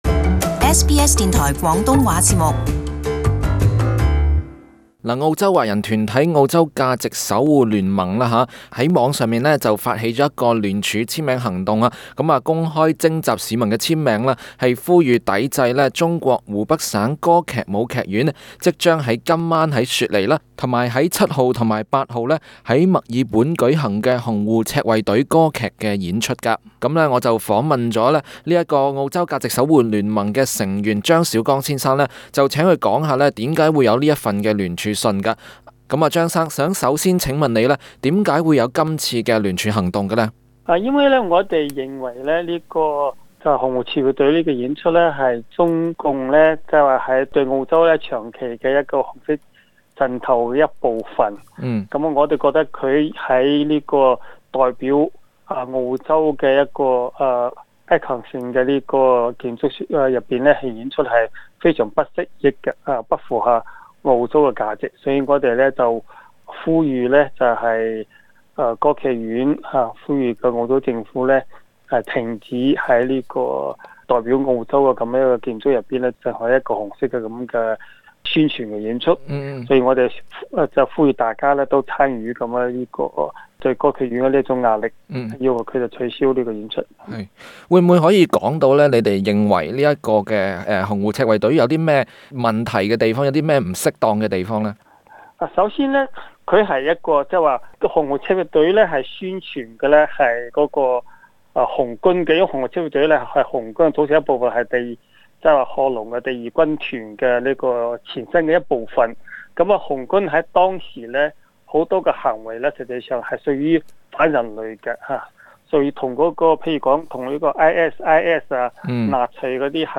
【社區專訪】聯署阻《洪湖》澳洲演出